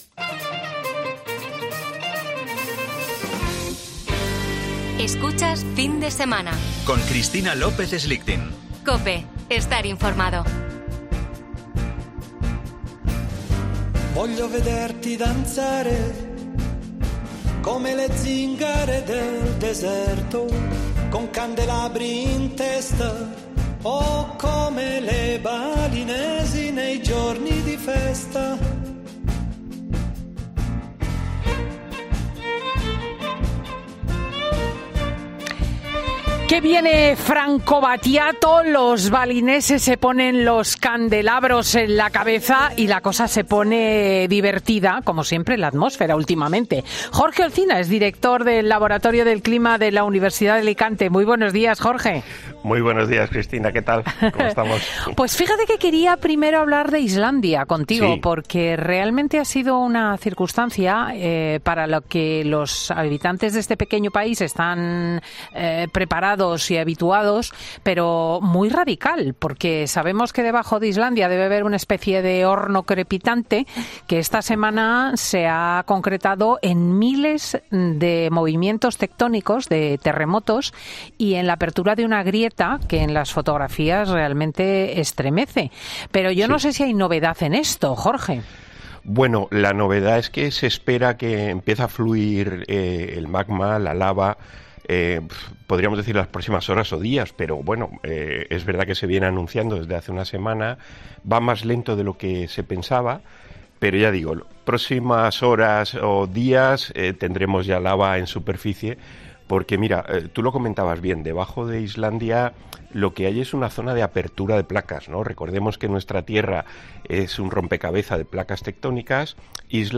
Un meteorólogo explica el fenómeno que llegará a España a partir del lunes y que traerá muchos cambios